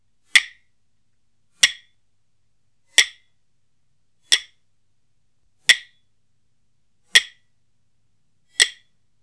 4.2.2.1.CẶP KÈ hay SÊNH SỨA
Cốc: Ví dụ: (471-4a)